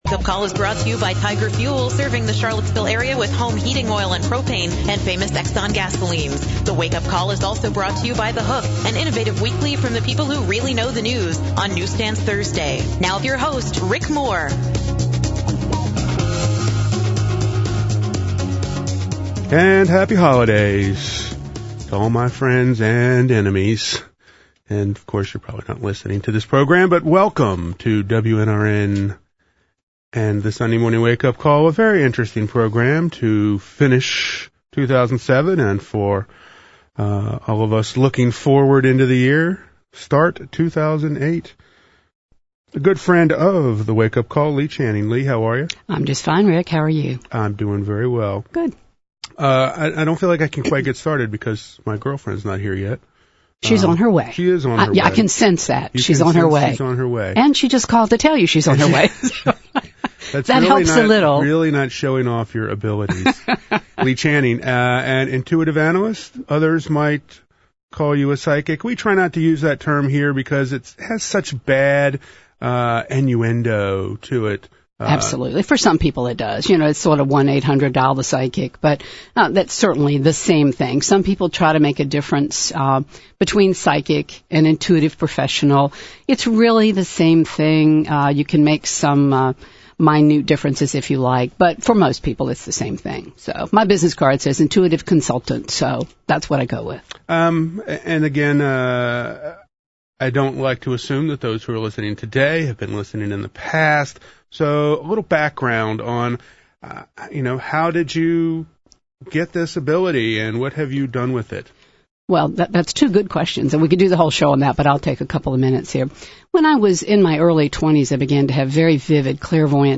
Particular callers ask about moving on from paralyzing breakups, reducing stress at work and in life, and anger management. Also covered are the meaning of color and the roles of chakras (zones of the body) in the life.